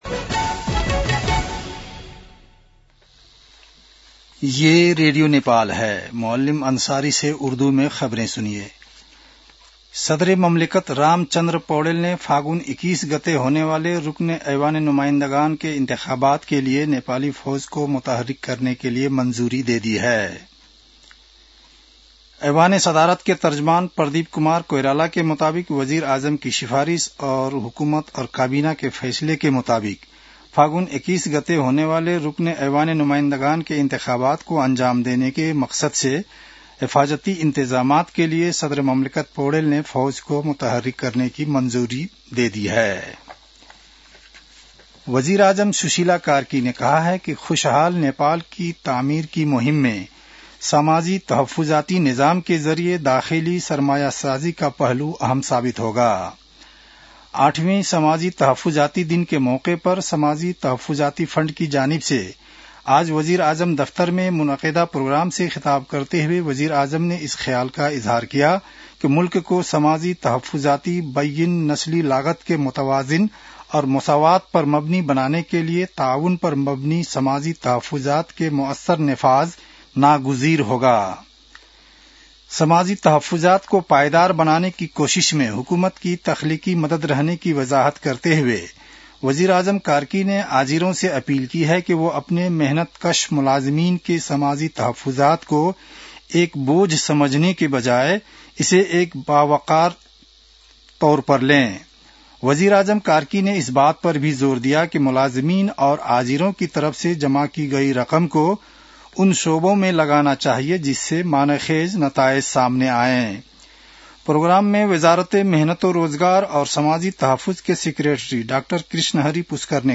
उर्दु भाषामा समाचार : ११ मंसिर , २०८२
Urdu-news-8-11.mp3